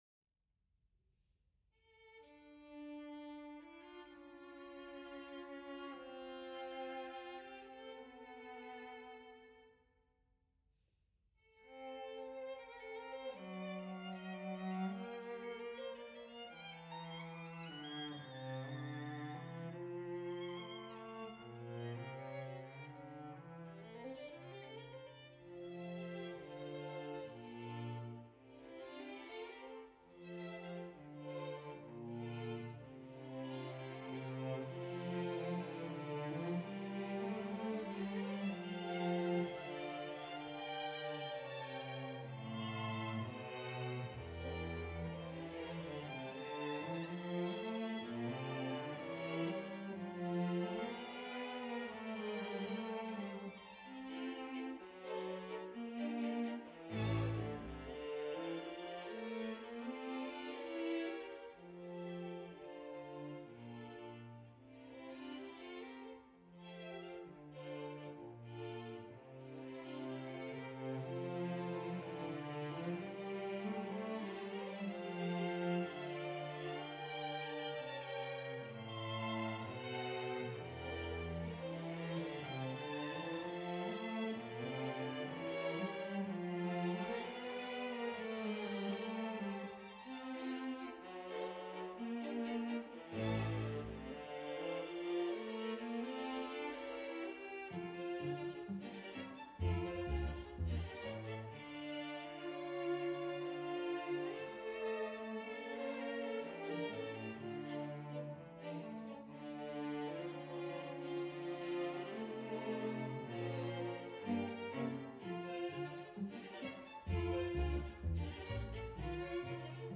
for string orchestra